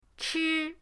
chi1.mp3